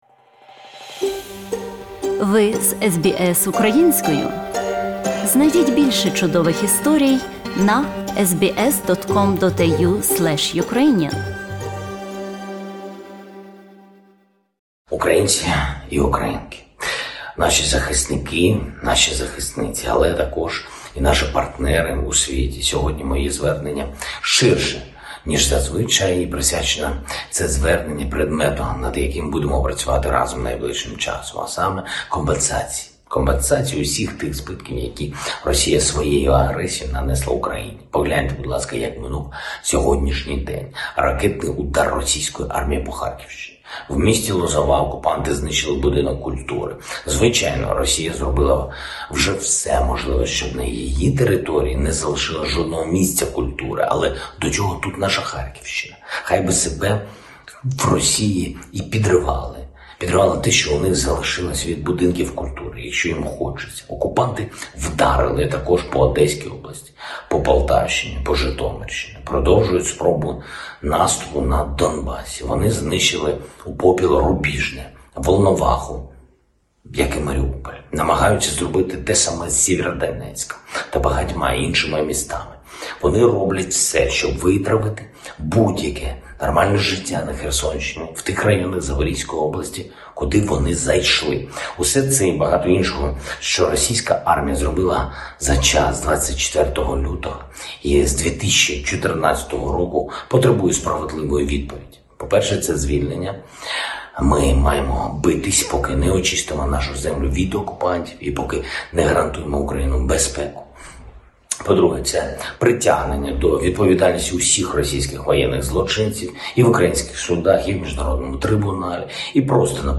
Ukrainian President V. Zelenskyy addresses Ukrainian nation